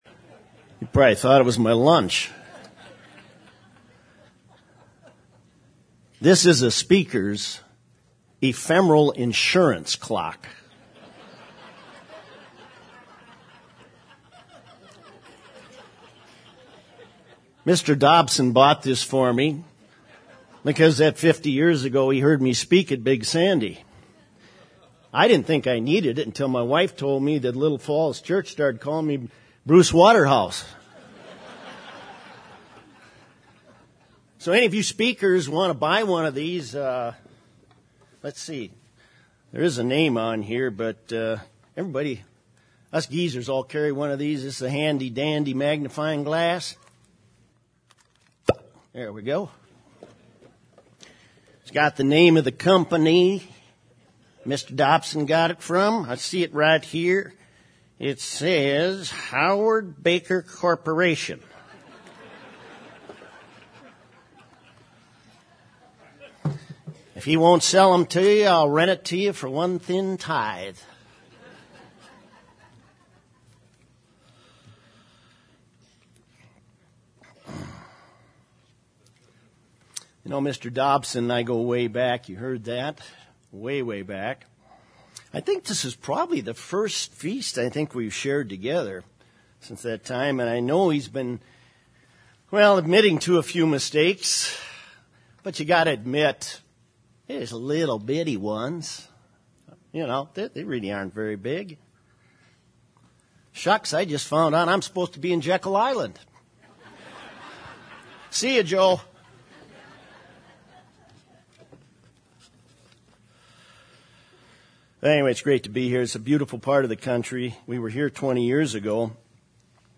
This sermon was given at the Ocean City, Maryland 2015 Feast site.